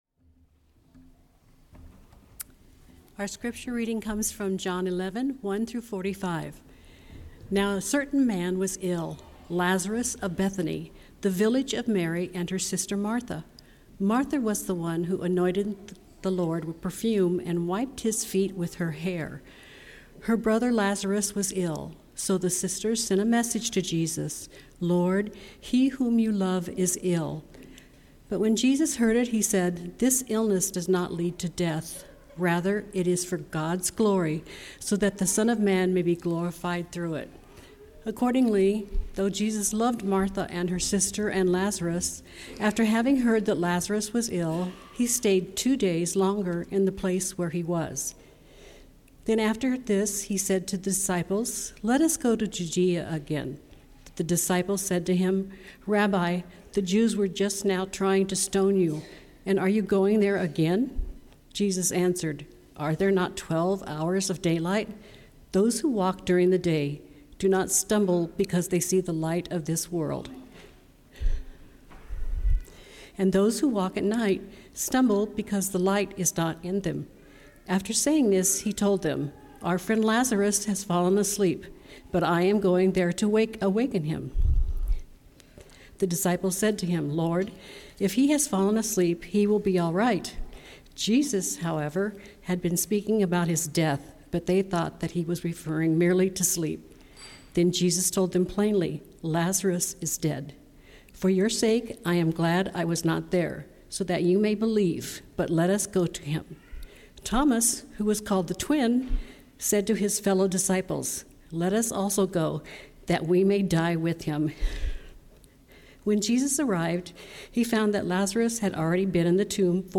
Sermons | Broadway United Methodist Church